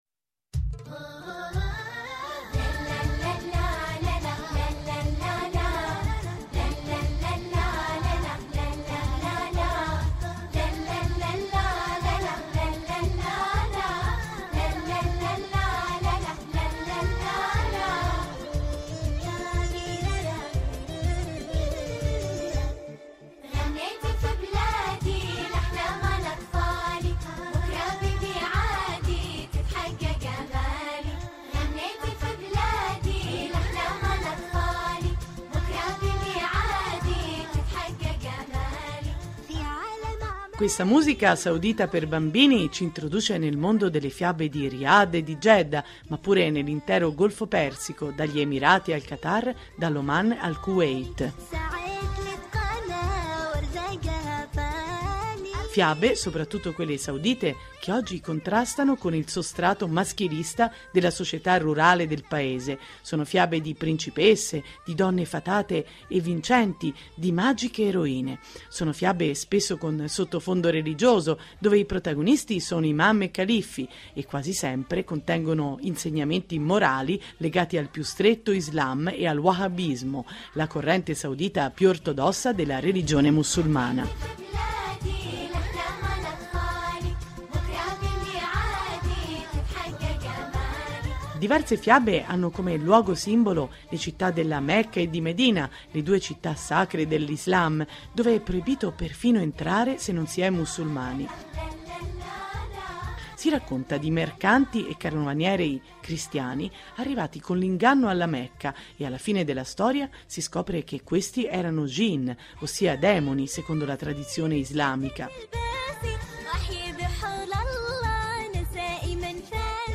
E poi fiabe narrate dai ragazzi dell’Oman e un coro di bambini del Kuwait che intona un motivo ispirato a fiabe locali.